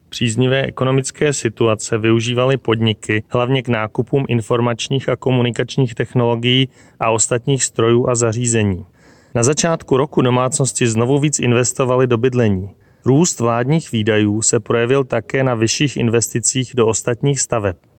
Vyjádření předsedy ČSÚ Marka Rojíčka, soubor ve formátu MP3, 705.14 kB